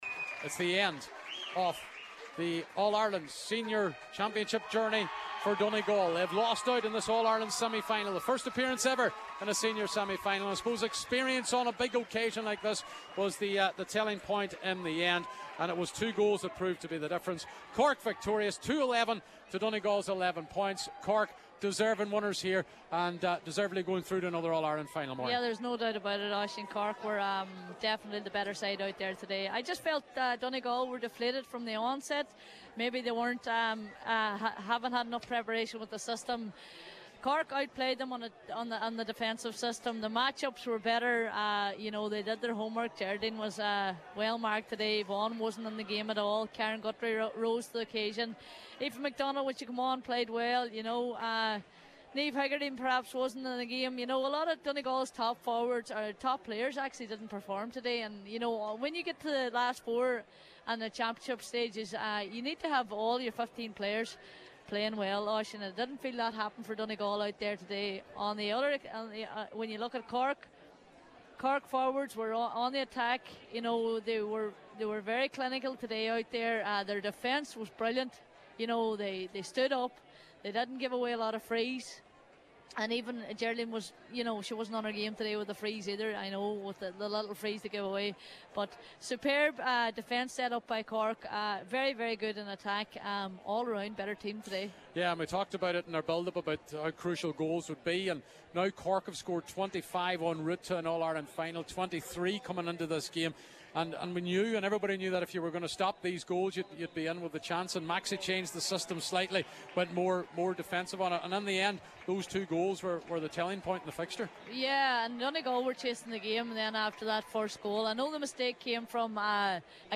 commentary on the final seconds is followed by a discussion on the game